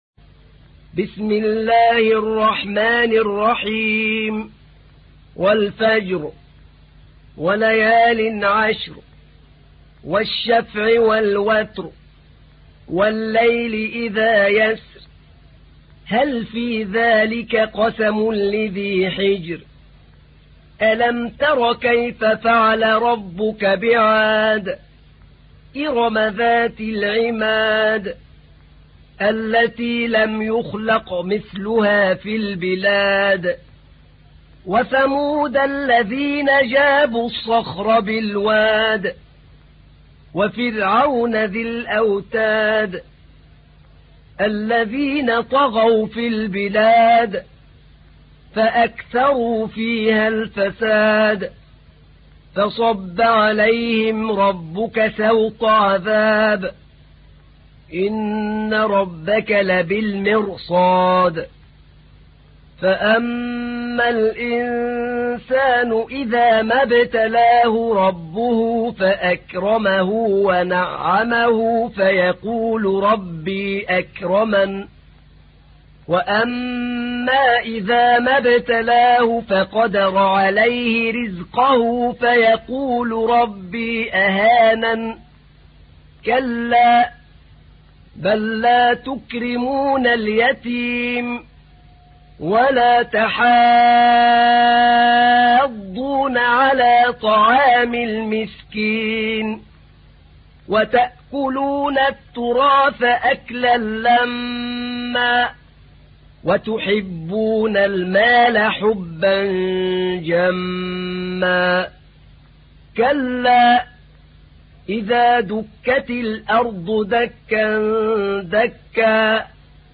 تحميل : 89. سورة الفجر / القارئ أحمد نعينع / القرآن الكريم / موقع يا حسين